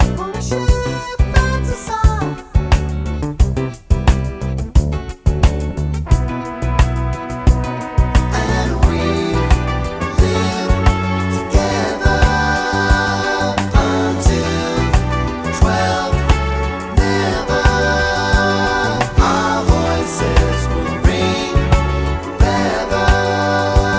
no Backing Vocals Disco 3:52 Buy £1.50